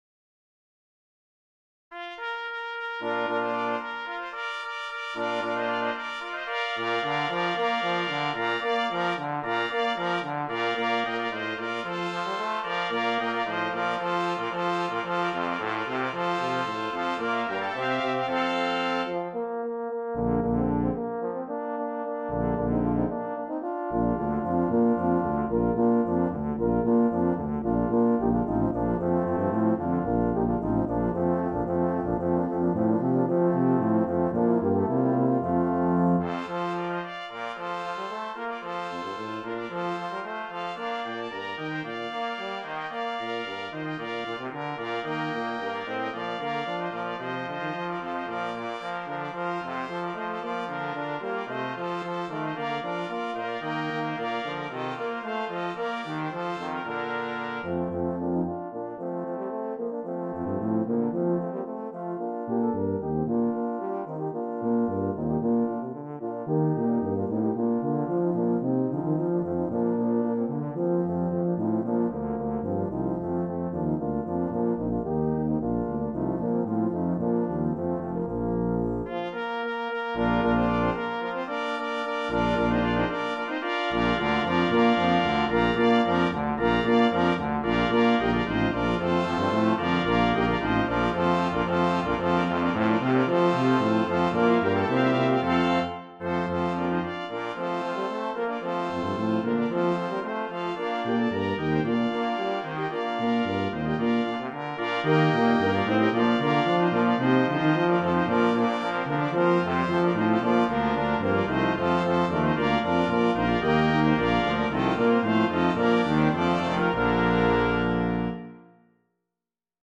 3 Trumpets
2 Horns in F
2 Trombones
Euphonium
Tuba
for Brass Nonet